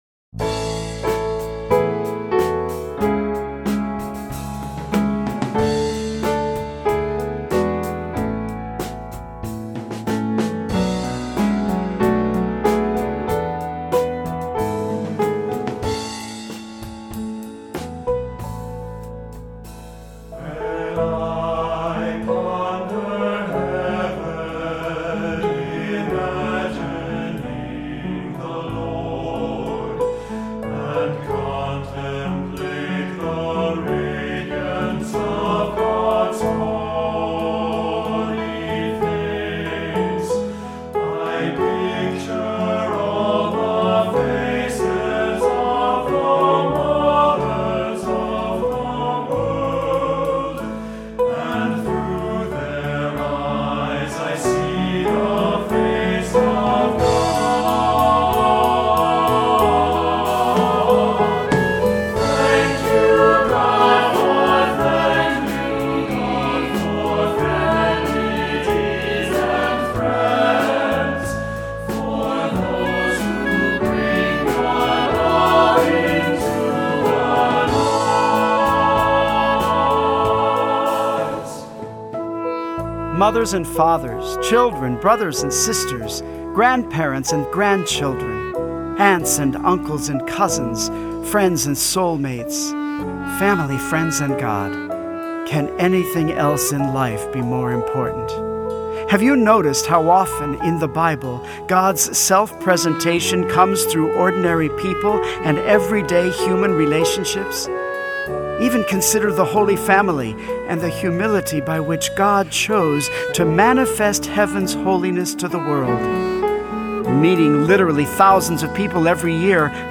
Voicing: Assembly,SATB, descant, cantor